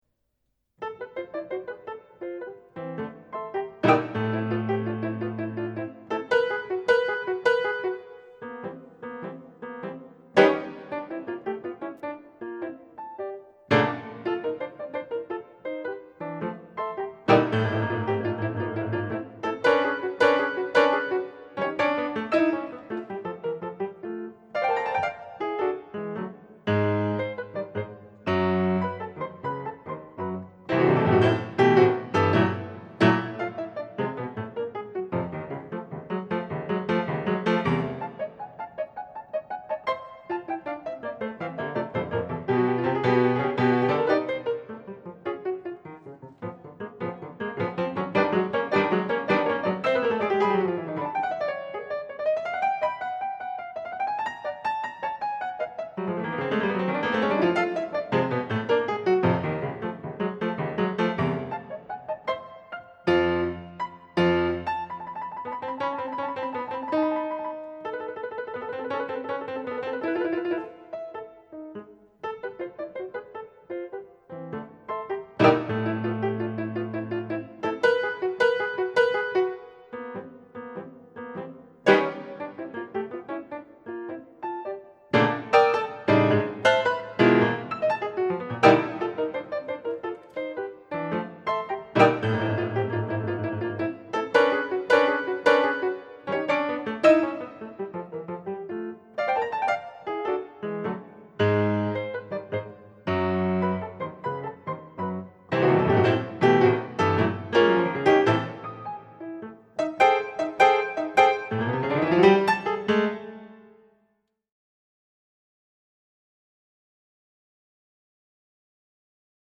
per pf a 4 mani